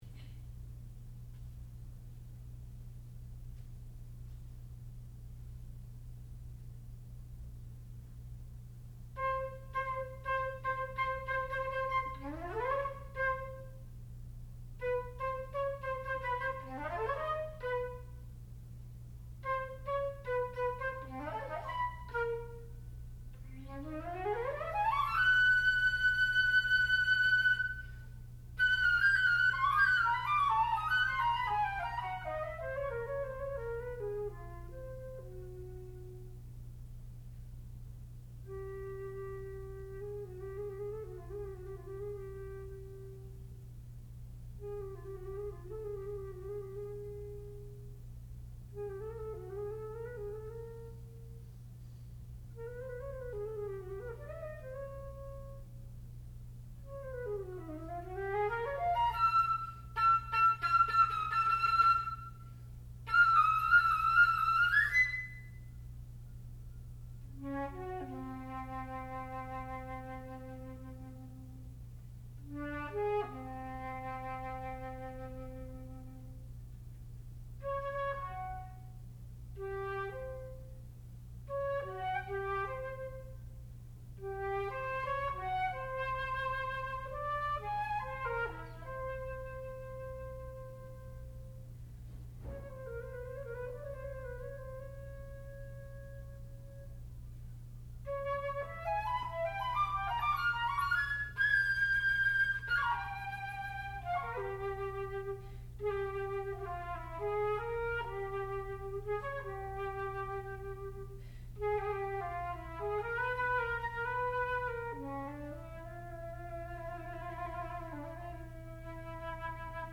sound recording-musical
classical music
flute
Advanced Recital